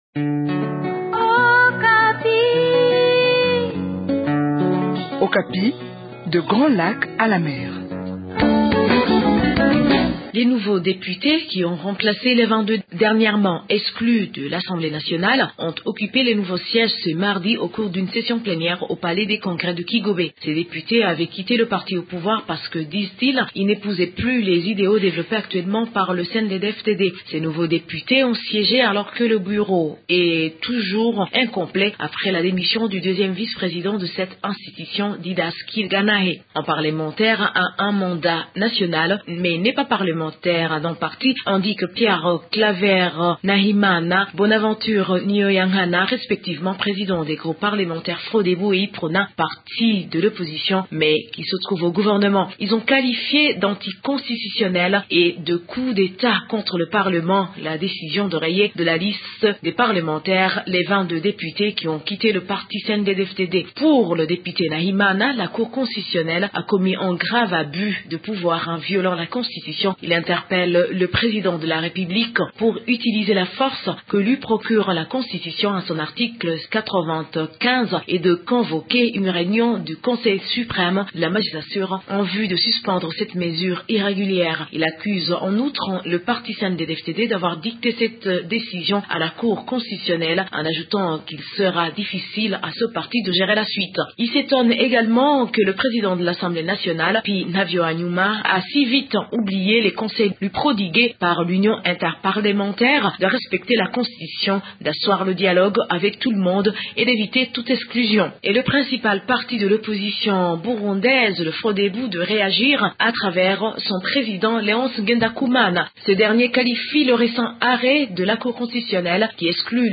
De Bujumbura, une correspondance